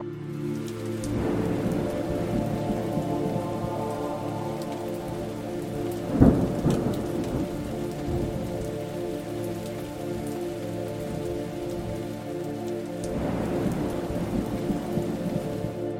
سمپل فضاسازی پد Elysium | هنر صدا
تعداد: 24 آکورد مینور و ماژور
13 تک نت جهت ساخت انواع آکوردها
از پکیج های سری امبینت
• دکلمه: فراهم کردن پس‌زمینه‌ای آرام و دلنشین برای دکلمه‌ها